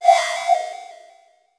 overfillwarning.wav